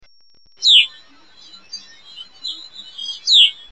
Hutton's Vireo (Vireo huttoni)
i. Song: double-noted zu-weep, with rising inflection, sometimes continuously repeated; vireo quality (P).
Given as either a slow version or a fast version.